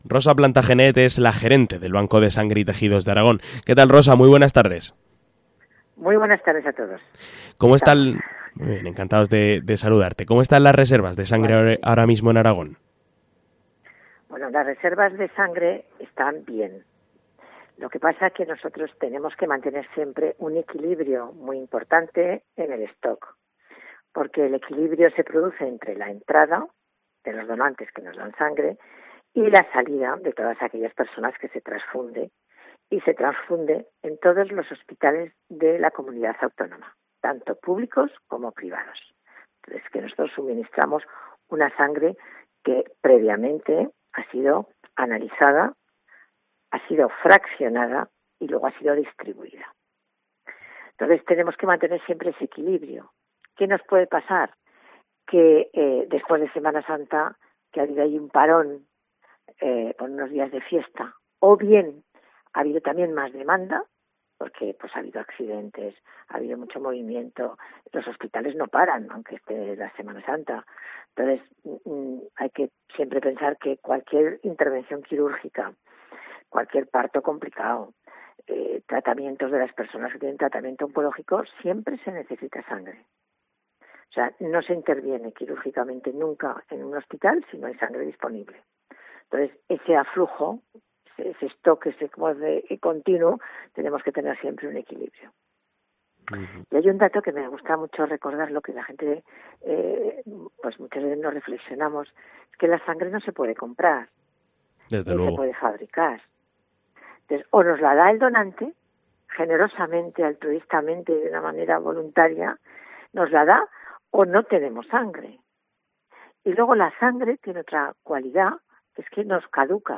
En Directo COPE TERUEL